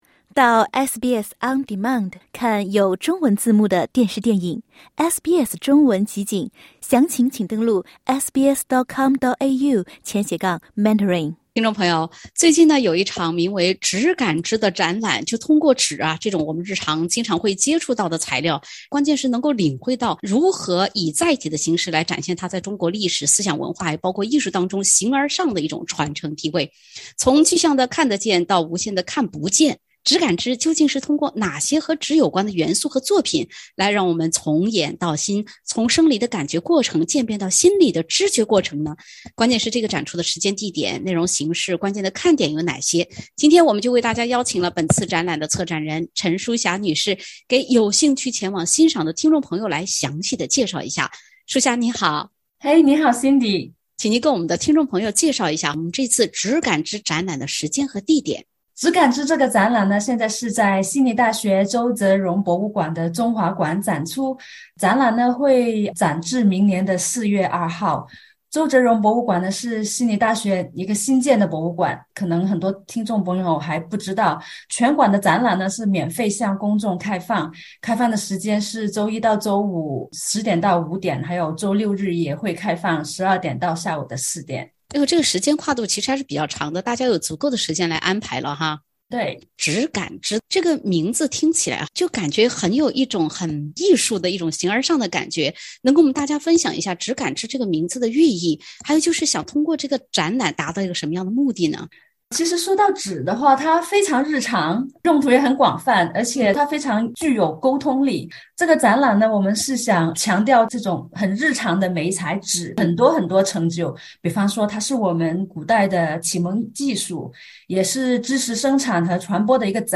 憋了很久想看展览的朋友们可以关注悉尼近期一直将持续到明年四月的“纸·感·知”展览。（点击封面图片，收听完整采访）